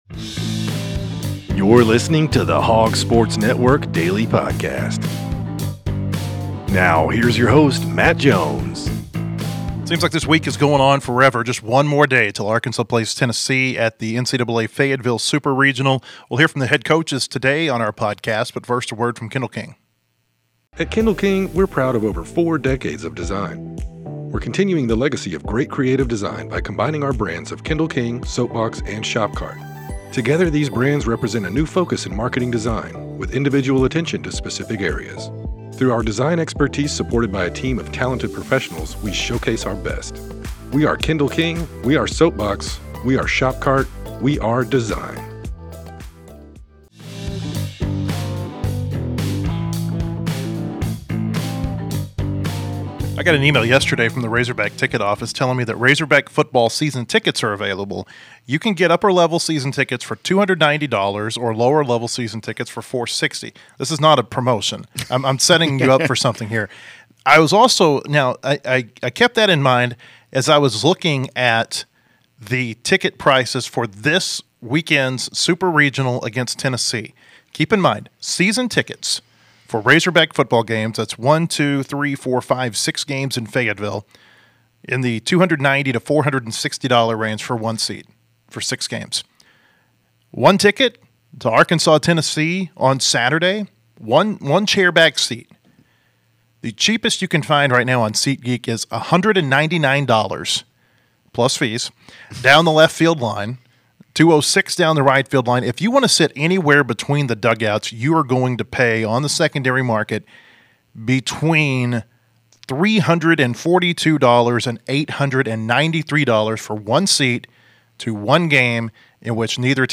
and we hear thoughts from Dave Van Horn and Tony Vitello.&nbsp